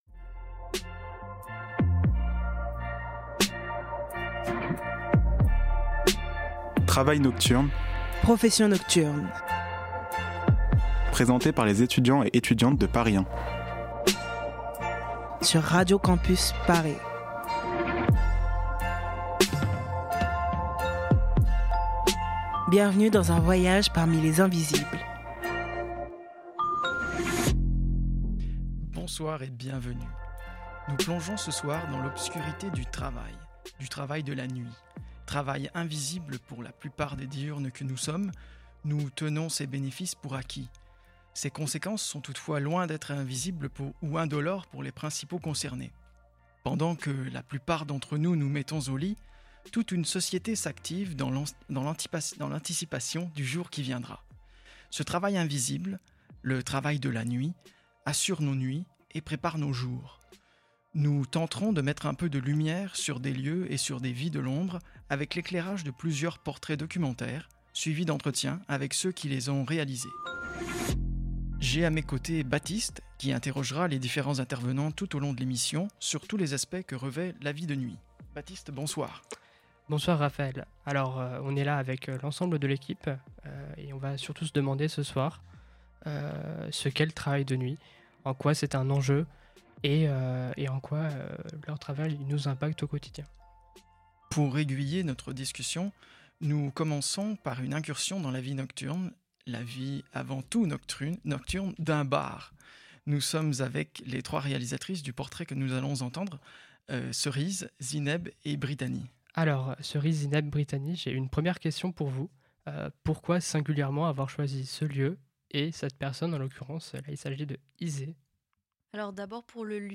Des reportages et fictions pour plonger dans l'ambiance du travail de nuit :